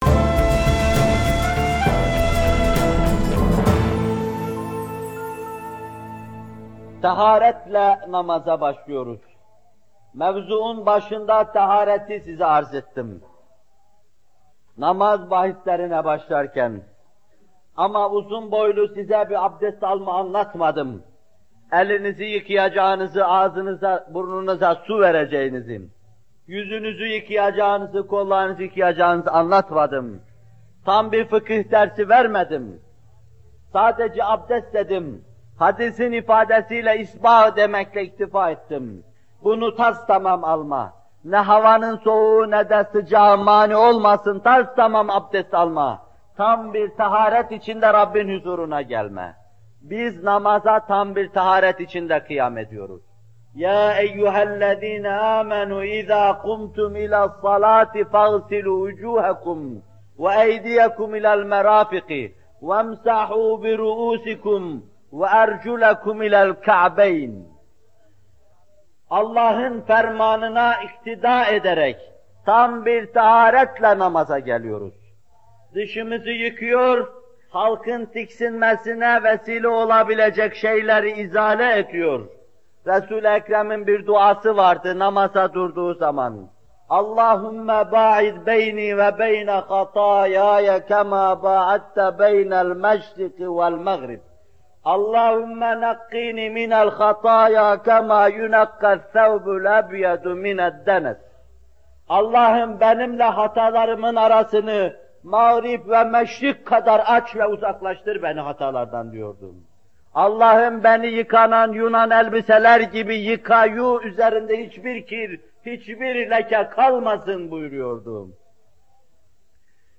Bu bölüm Muhterem Fethullah Gülen Hocaefendi’nin 22 Eylül 1978 tarihinde Bornova/İZMİR’de vermiş olduğu “Namaz Vaazları 6” isimli vaazından alınmıştır.